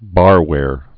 (bärwâr)